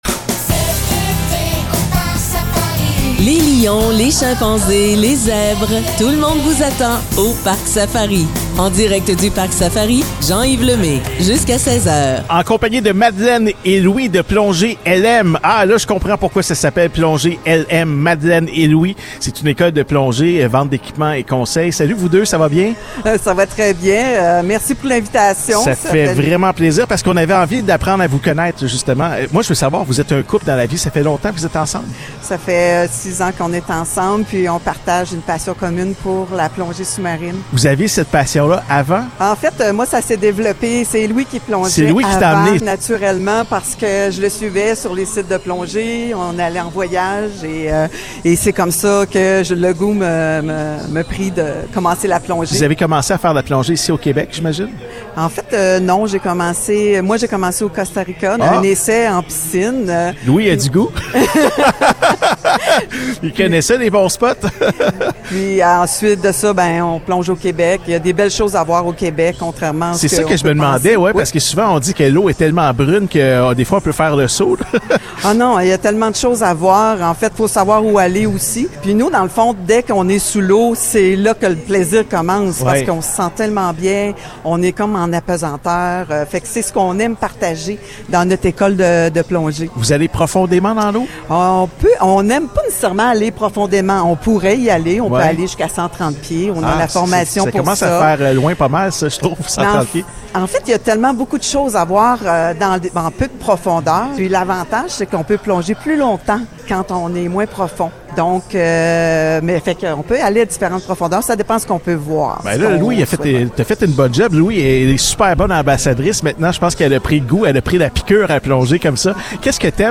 Plongée LM en entrevue!